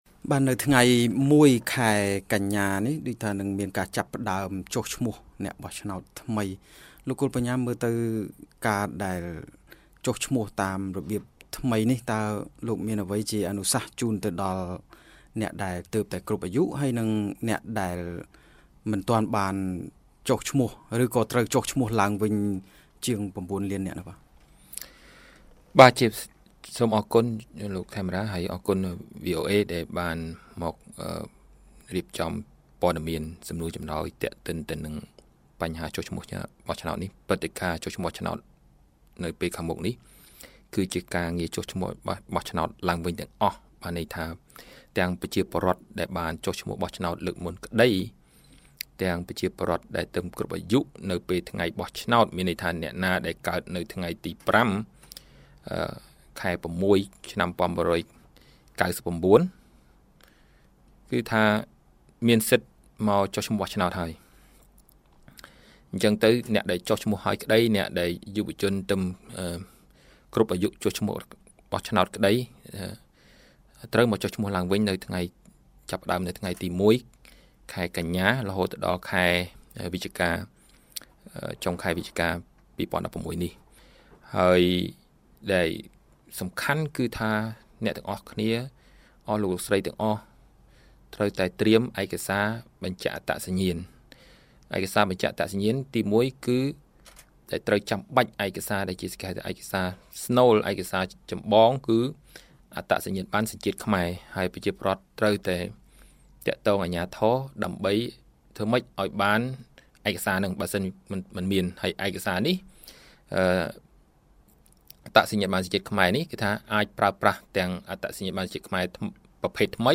បទសម្ភាសន៍ VOA៖ ដំណើរការរៀបចំការបោះឆ្នោតជាថ្មីតាមប្រព័ន្ធកុំព្យូទ័រ(ភាគទី១)